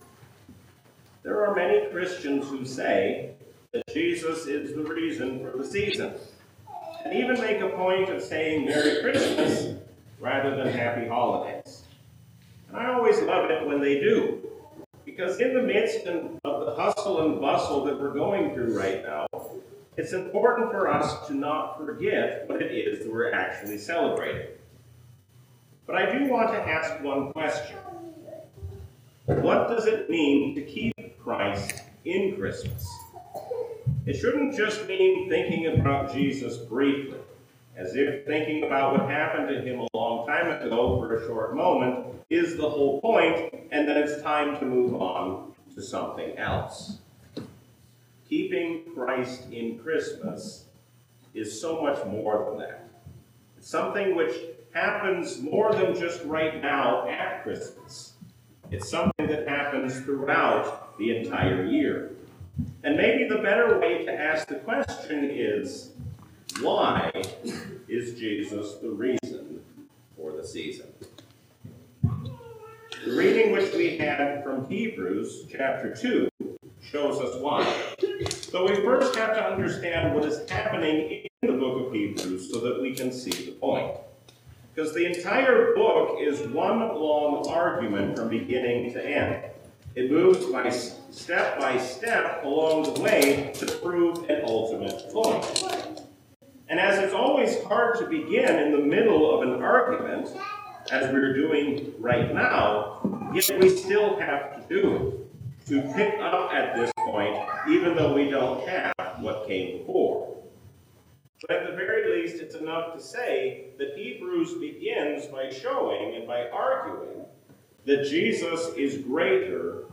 A sermon from the season "Christmas 2024."